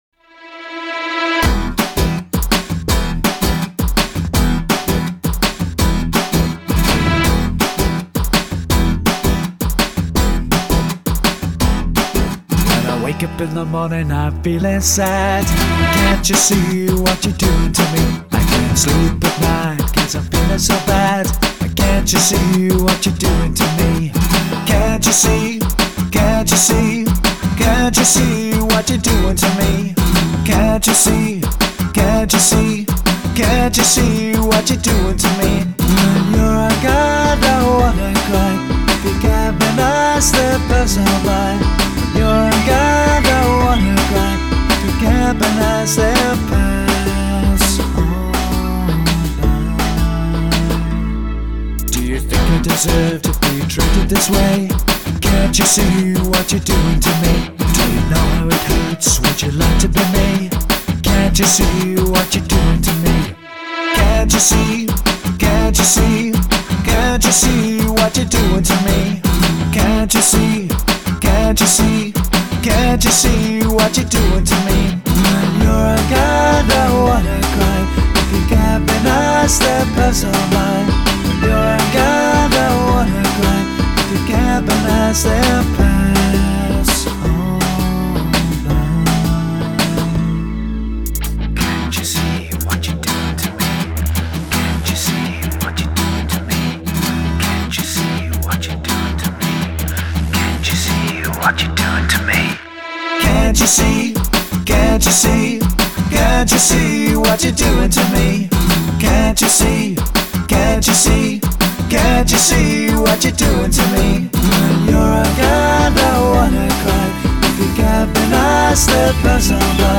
MALE Version